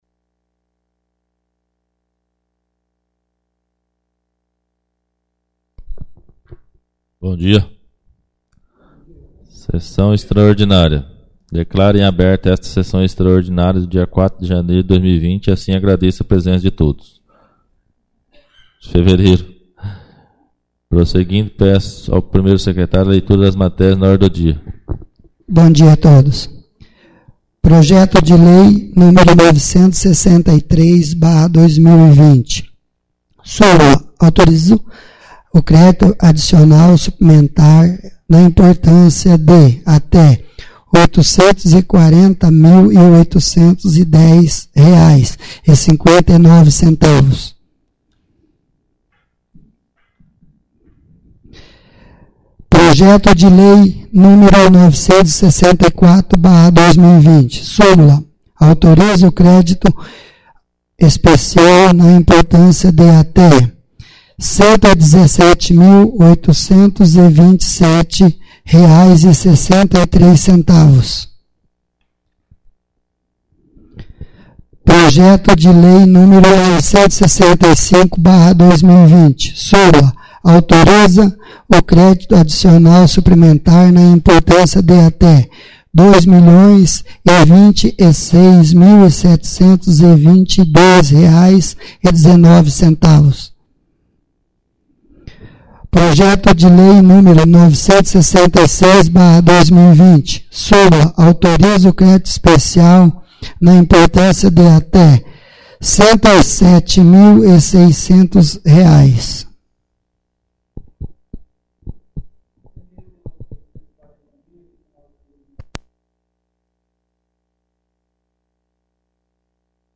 2ª Extraordinária da 4ª Sessão Legislativa da 11ª Legislatura